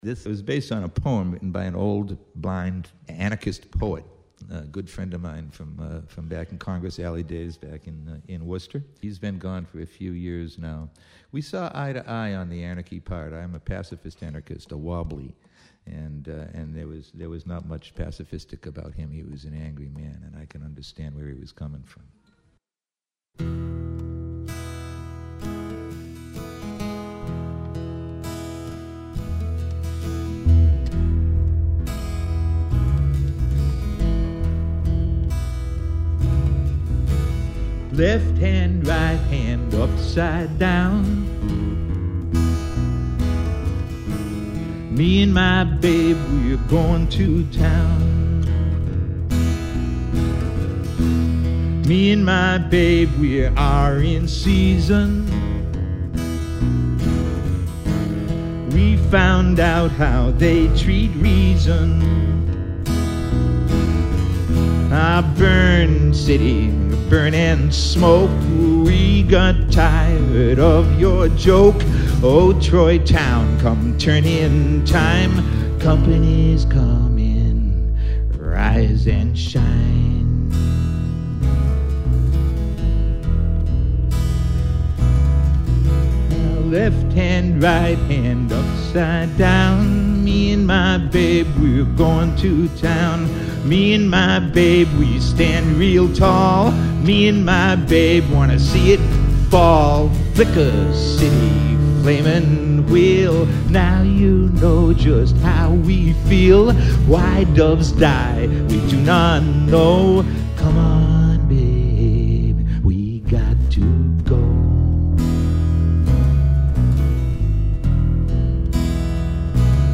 Live Recordings: at ROM
vocals, guitar, bass guitar
Location: Roslindale, MA